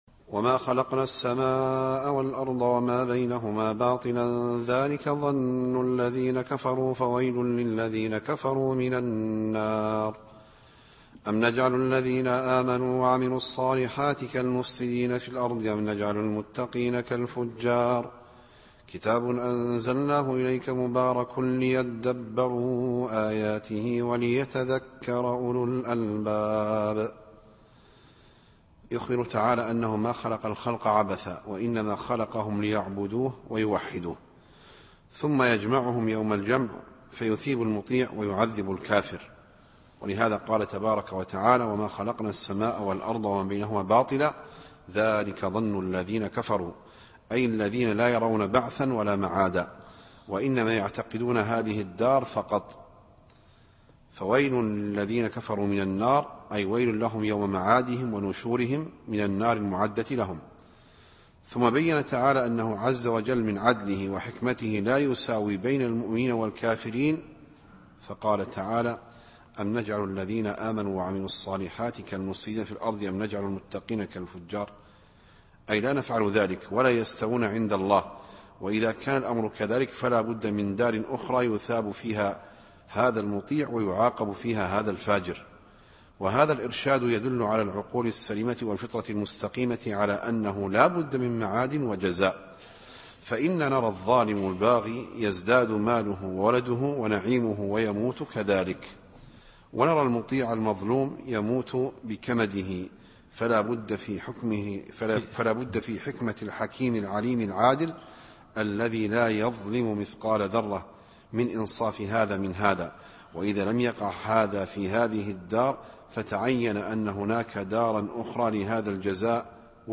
التفسير الصوتي [ص / 29]